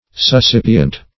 Search Result for " suscipient" : The Collaborative International Dictionary of English v.0.48: Suscipient \Sus*cip"i*ent\, a. [L. suscipiens, p. pr. of suscipere.